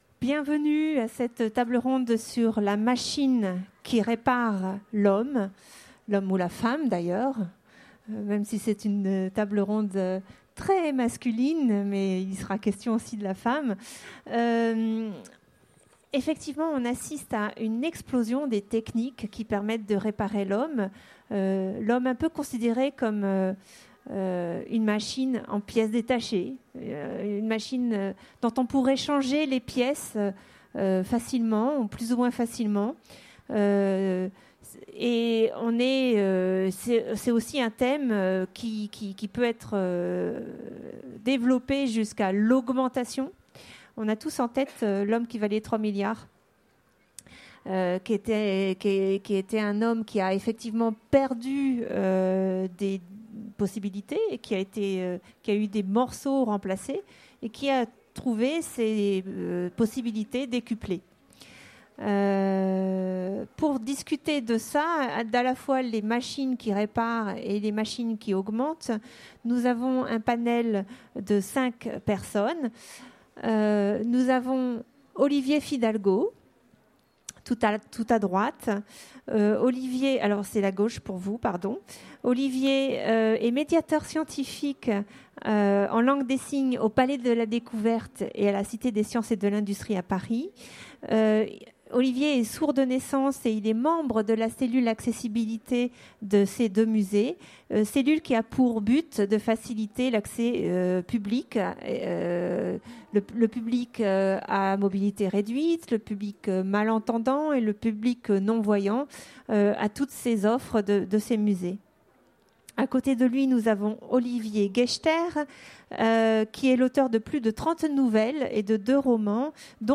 Utopiales 2016 : Conférence Quand la machine répare l’homme